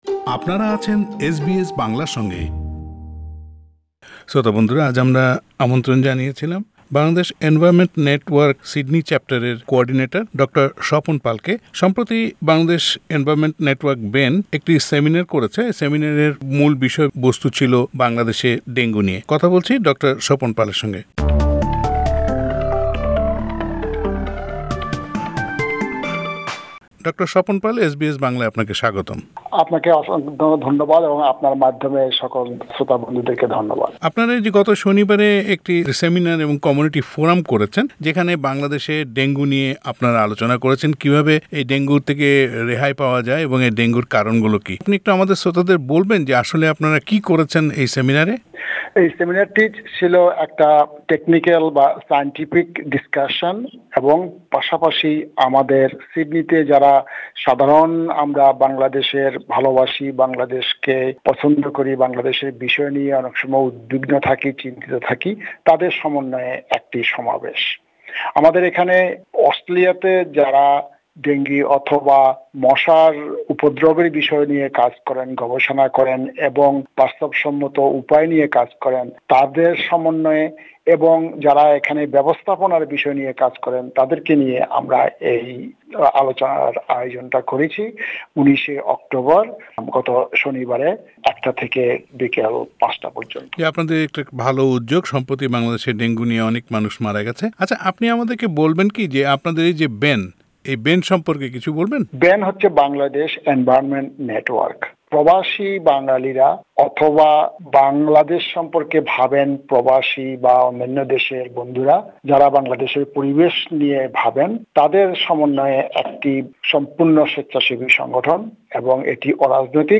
SBS Bangla